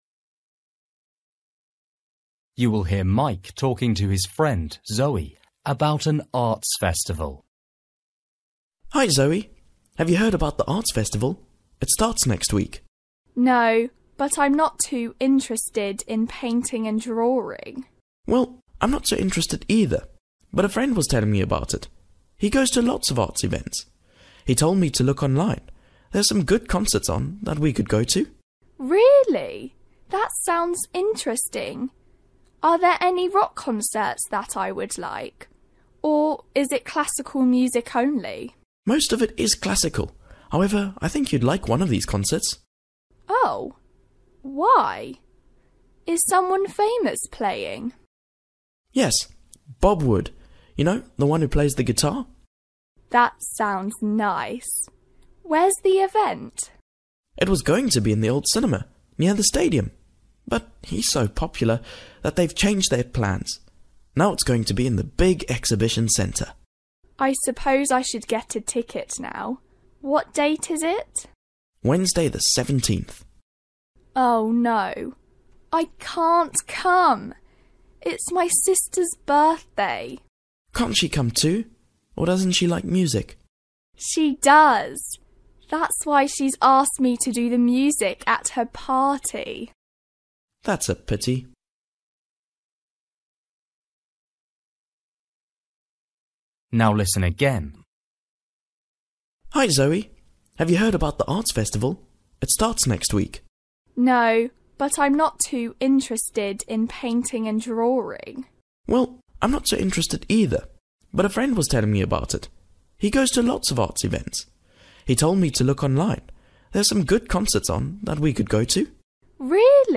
Bài tập trắc nghiệm luyện nghe tiếng Anh trình độ sơ trung cấp – Nghe một cuộc trò chuyện dài phần 16